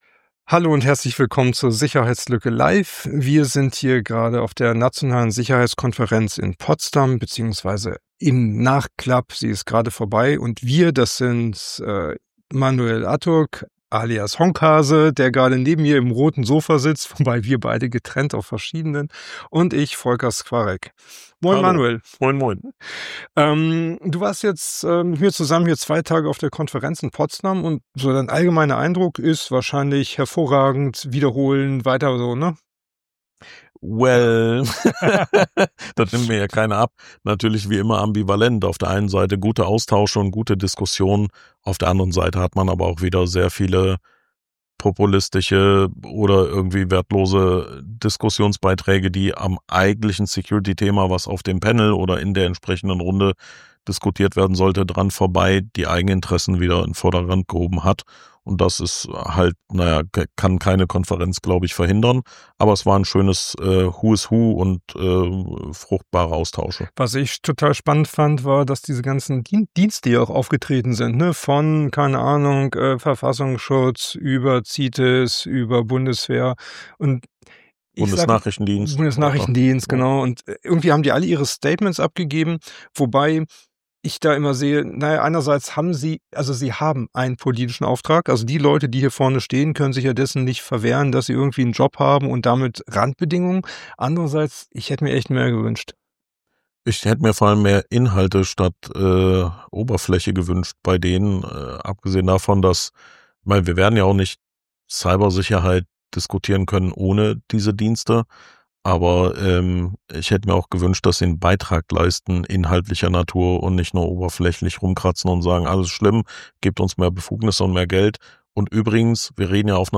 Die Sicherheits_lücke Live von der Konferenz für Nationale Cybersicherheit ~ HOOUcasts Podcast